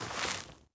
added stepping sounds
Ice_Mono_01.wav